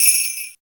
69 J.BELLS.wav